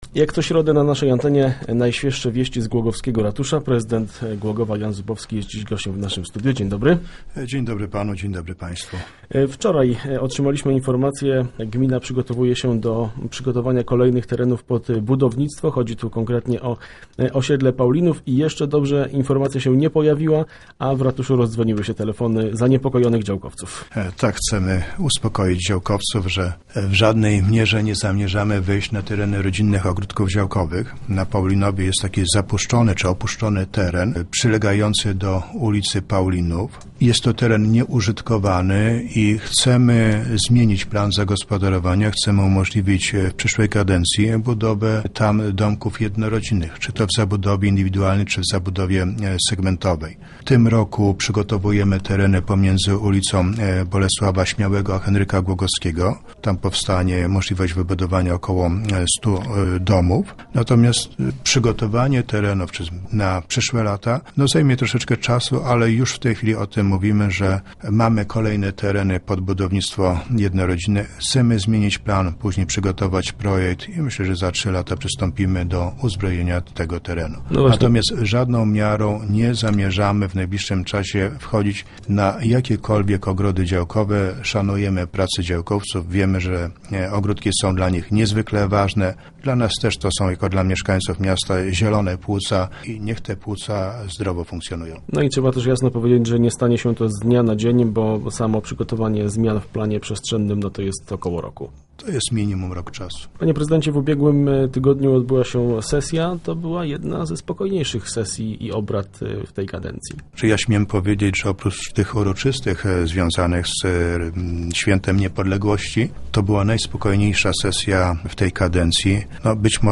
Już dziś  wiadomo, że zarówno rodzice, ale też gmina szkoły i  przedszkola będą miały spory problem. Między innymi o tym rozmawialiśmy z prezydentem Głogowa Janem Zubowskim w środowych Rozmowach Elki.